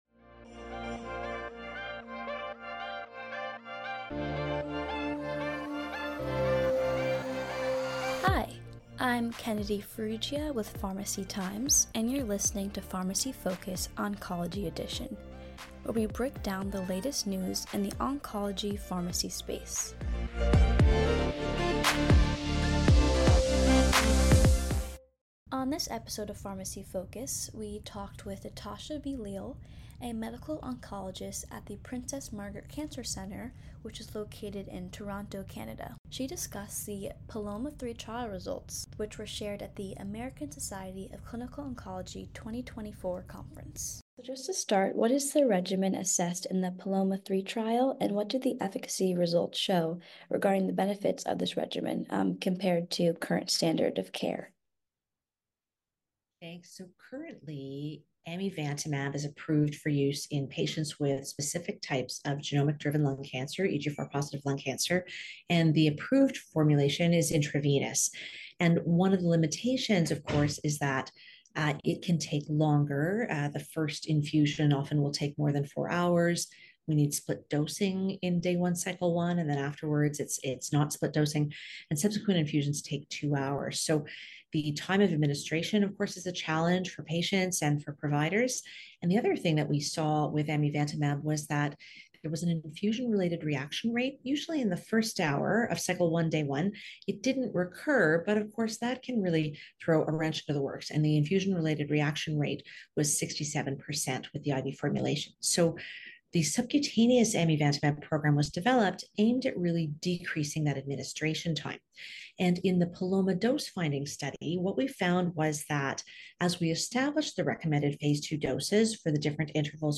On this episode, experts discuss the role of celebrity endorsements and trusted local figures in addressing vaccine hesitancy.